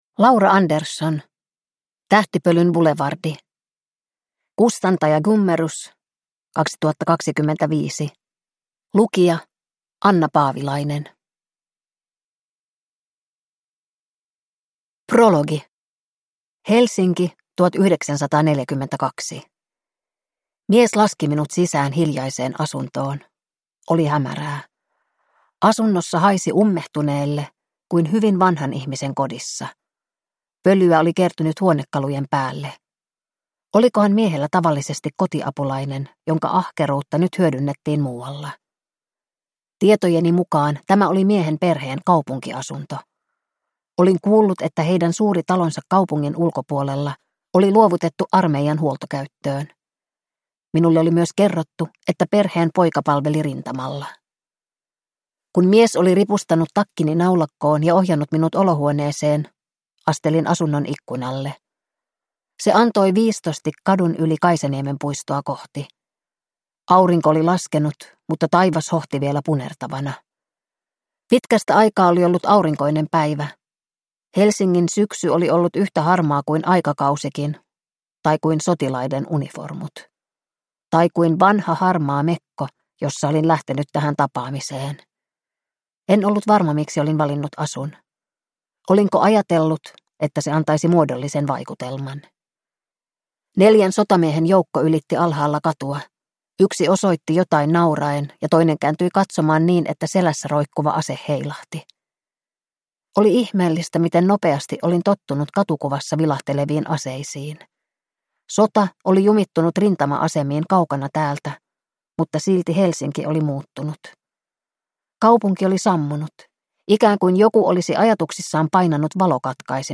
Tähtipölyn bulevardi (ljudbok) av Laura Andersson